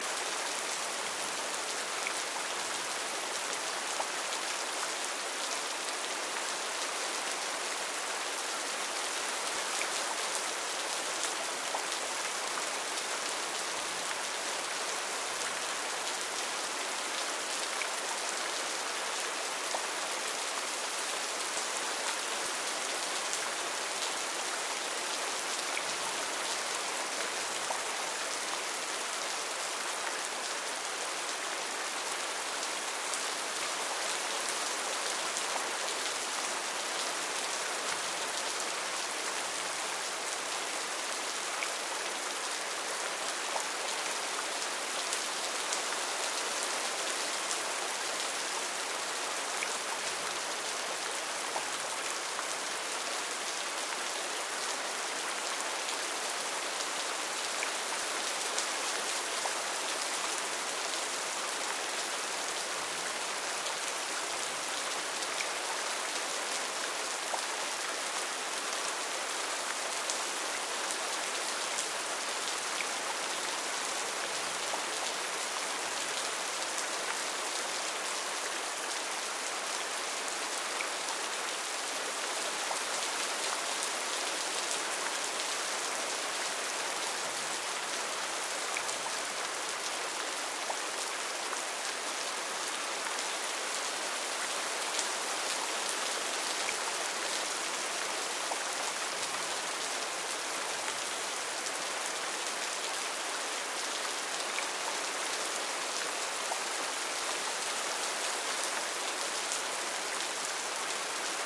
Мощный ливень в тропическом лесу